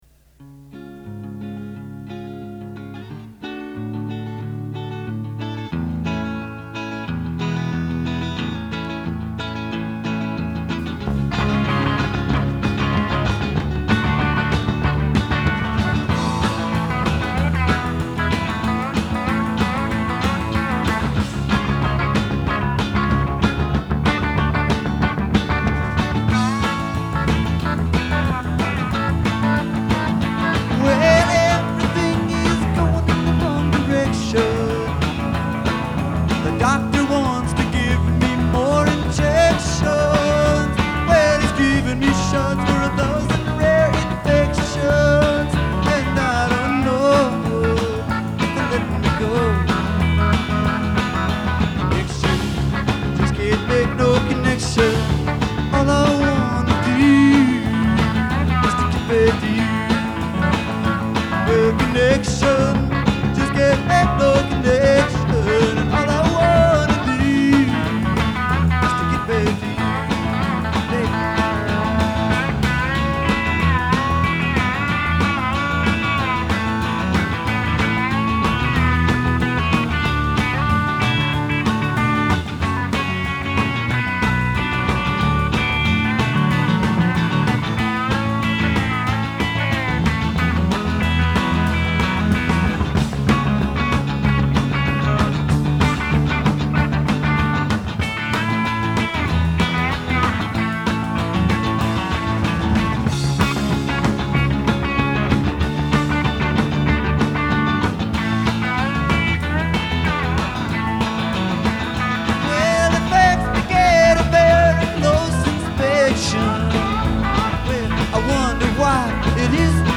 1972 at London's Lyceum Ballroom in an L4LM premiere.